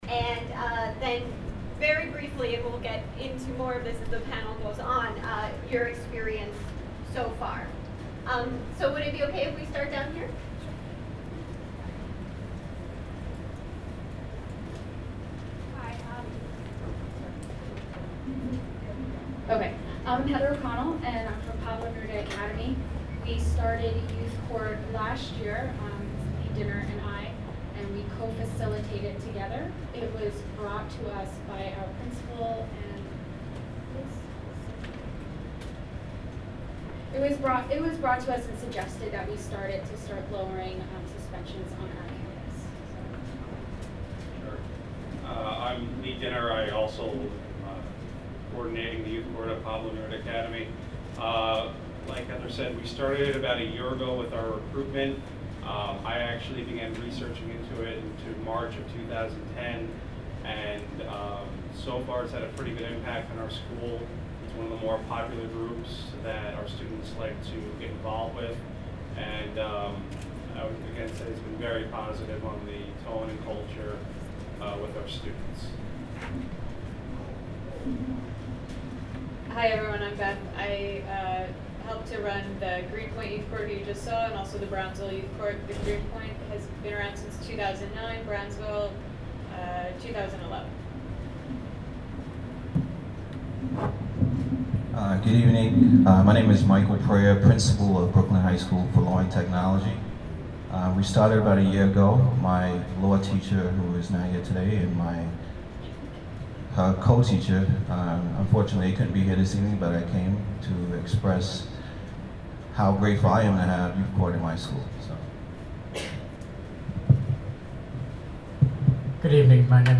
Panel Discussion on School-Based Youth Courts - Center for Justice Innovation
Listen to high school staff and administrators at the Center’s Youth Courts in Schools: A Peer-Based Approach to Discipline symposium discuss how introducing a school-based youth court affected their institutions. The discussion includes information about getting a youth court up and running, the challenges associated with operating a youth court, and the benefits of youth courts for participants, respondents, and the larger school community.